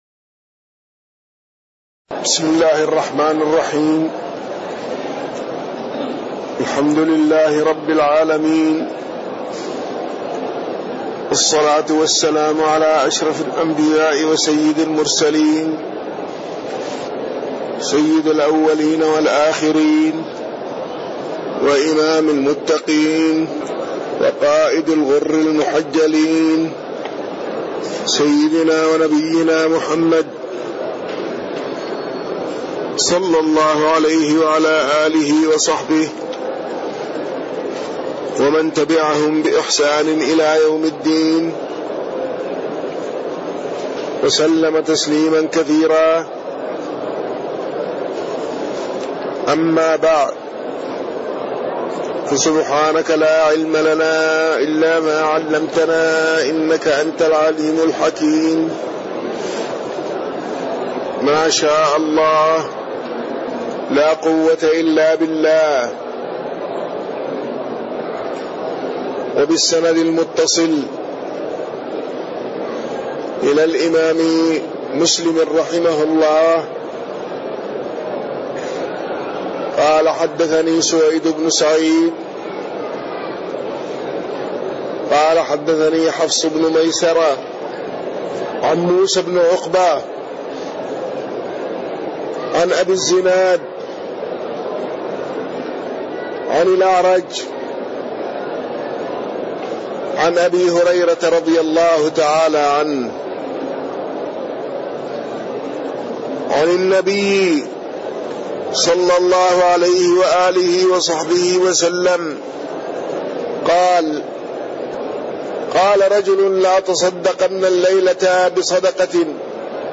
تاريخ النشر ١٦ شعبان ١٤٣٢ هـ المكان: المسجد النبوي الشيخ